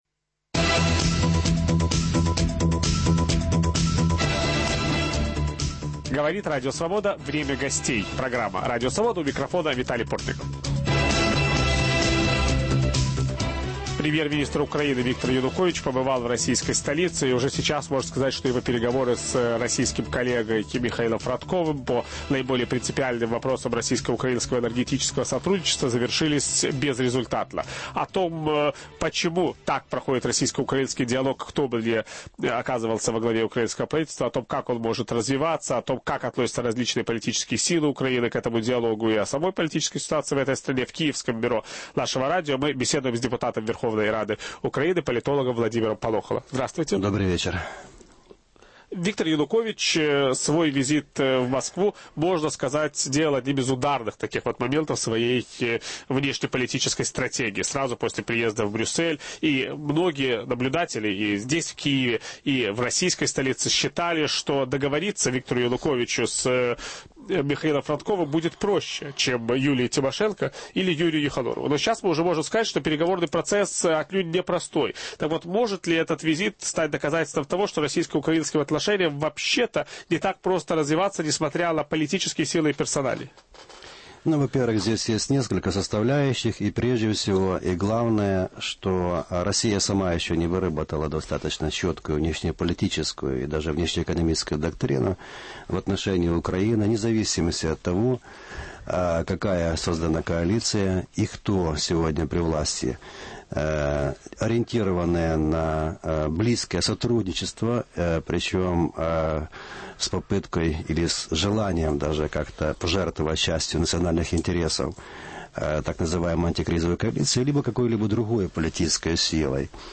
Российско-украинские отношения и визит Виктора Януковича в Москву. В студии - депутат Верховной Рады Украины политолог Владимир Полохало.